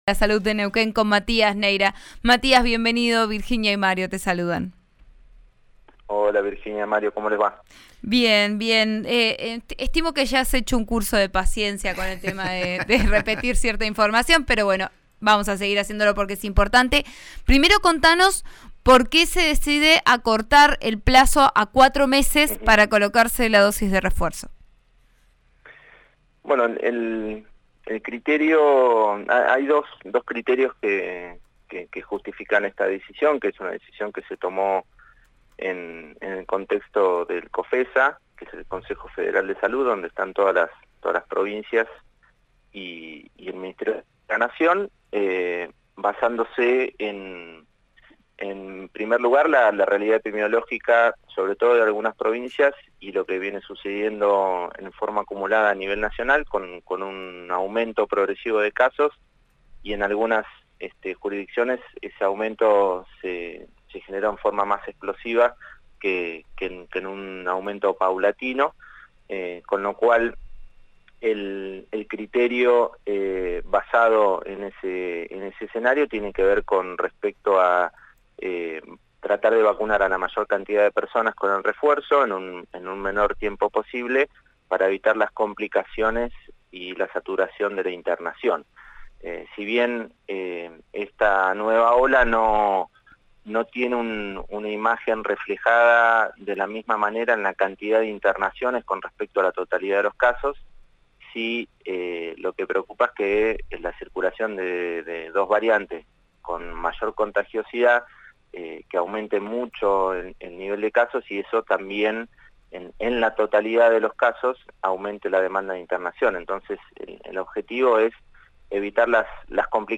Lo confirmó el director de Atención Primaria de la salud, Matías Neira en diálogo con Vos a Diario de RN RADIO.